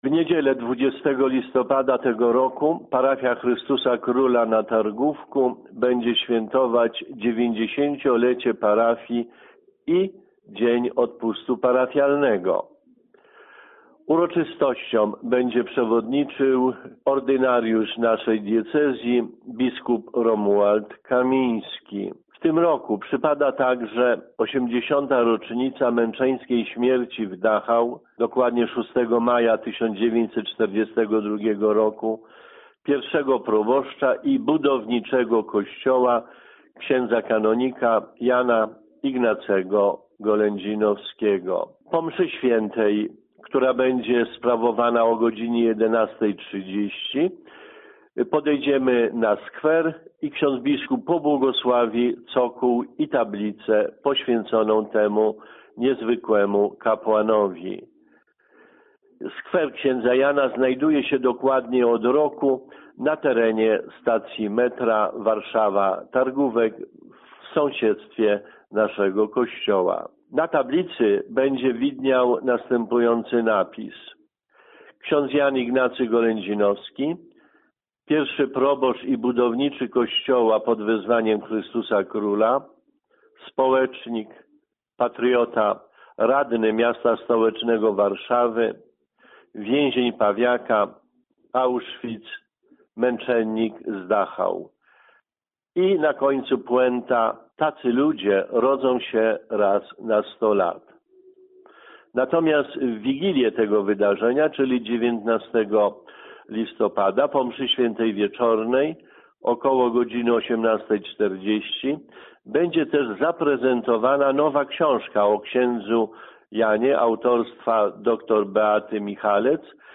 Wypowiedź ks.